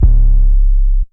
808 Yosemite.wav